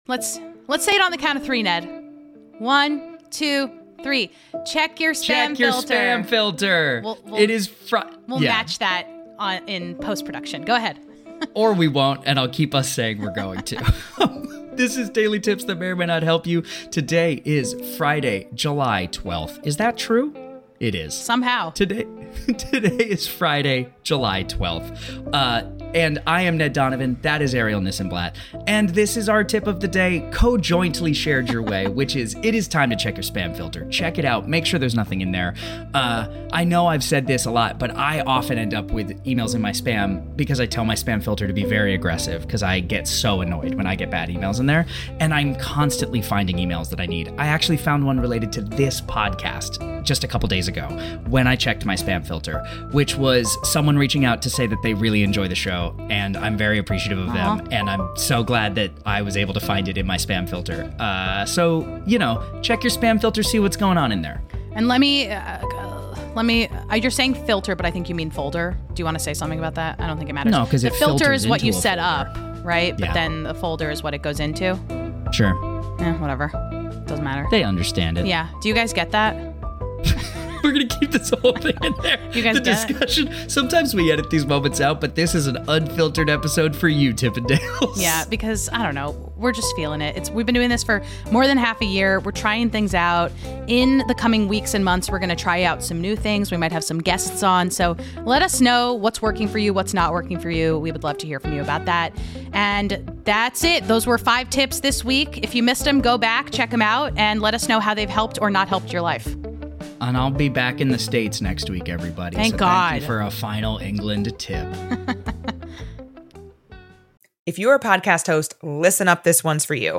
Also you can tell we’re at the end of a long week by the lack of editing on this one.